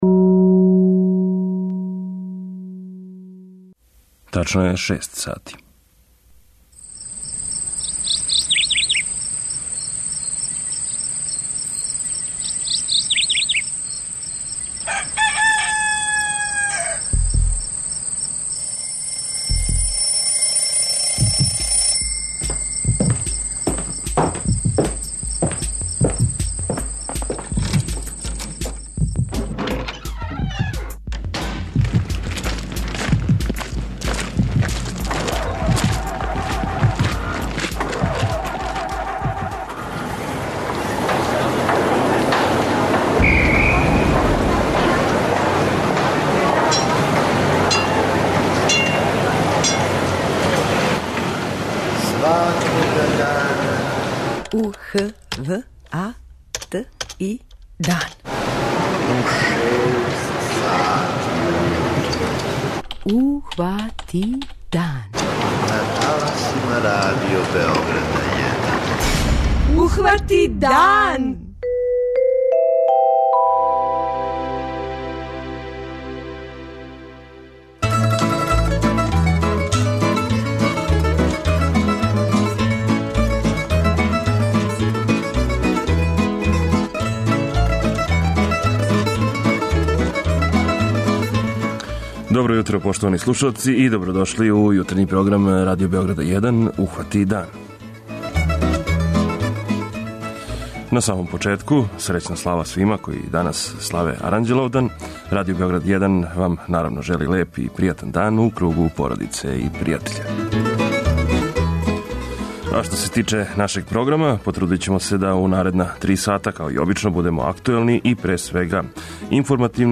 преузми : 85.93 MB Ухвати дан Autor: Група аутора Јутарњи програм Радио Београда 1!